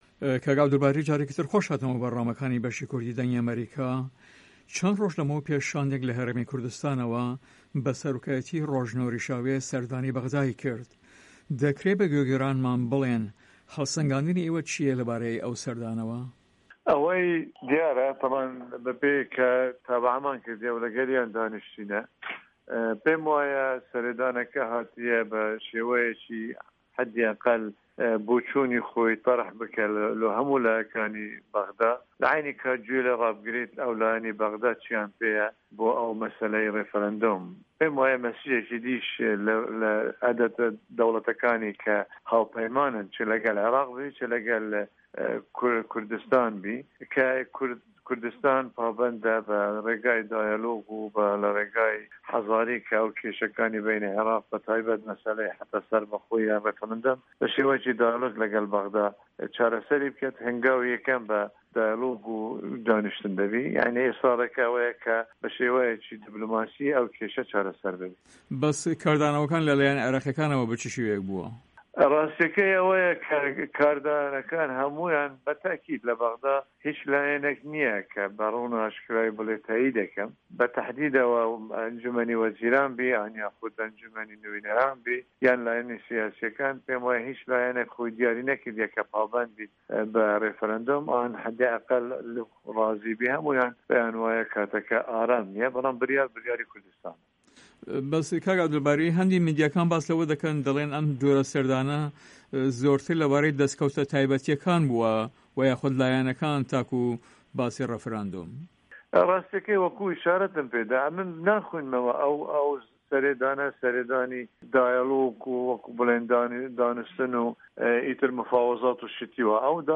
Interview with Abdulbari Zibari